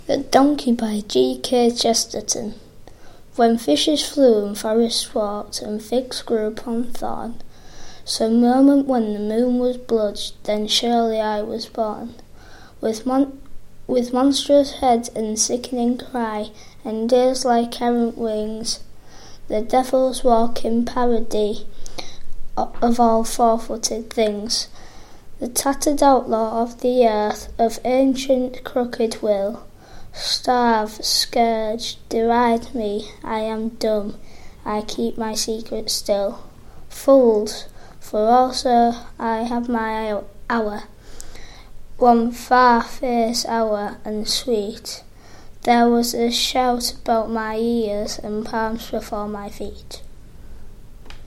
Poetry and Readings